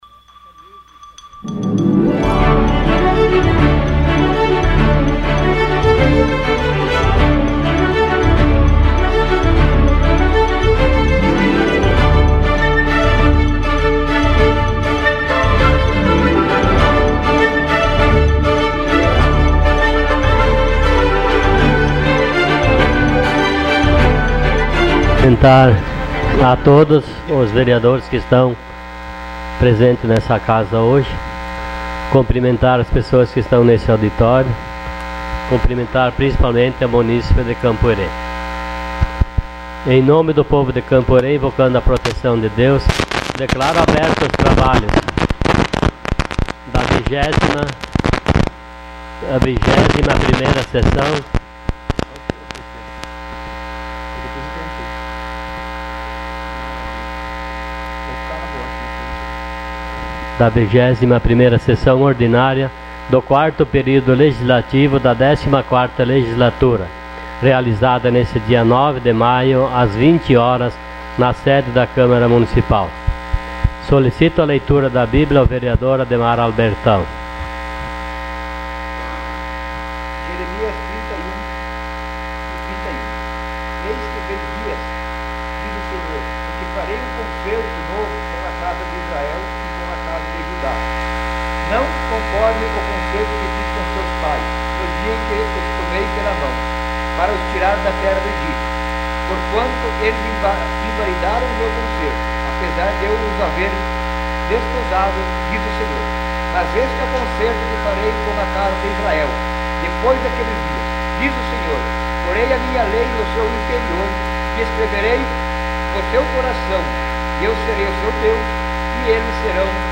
Sessão Ordinária dia 09 de maio de 2016.